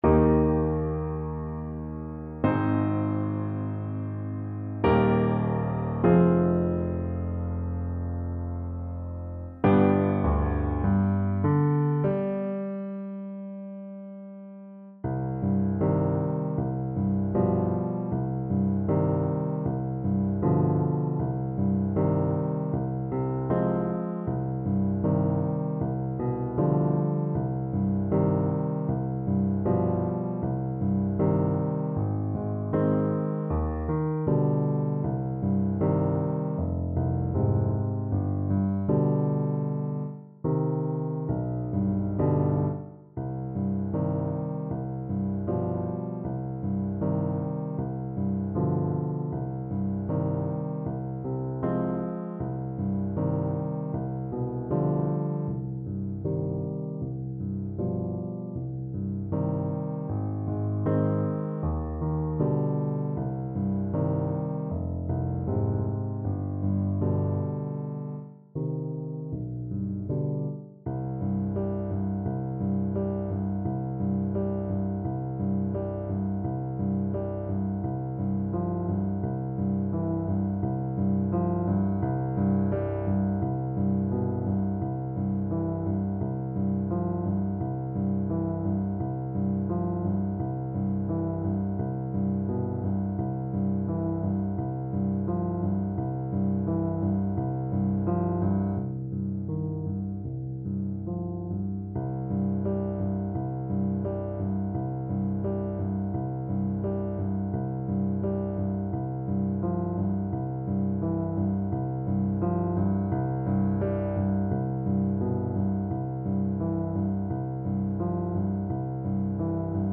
Largo
Classical (View more Classical Saxophone Music)